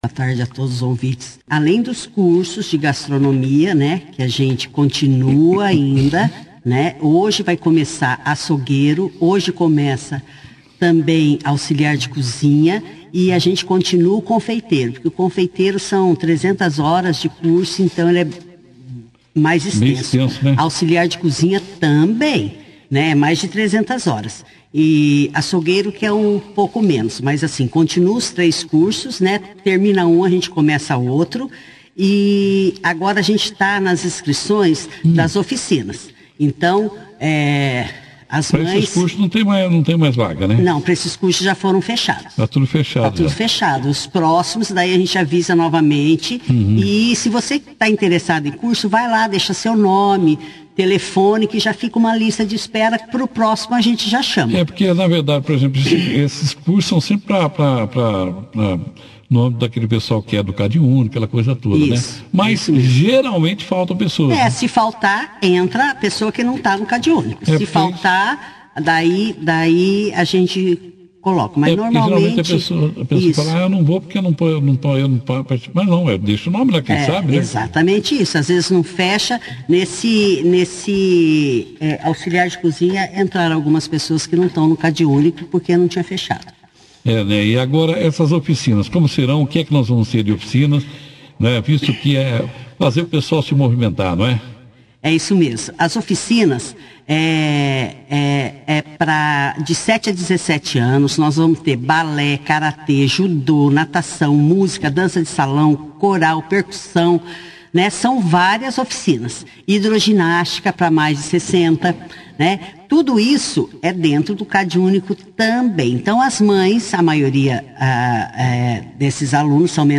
A Secretária de assistência social e assuntos da família, Mônica Zanardo de Sordi, (foto), participou da 2ª edição do jornal Operação Cidade desta segunda-feira, 15/08.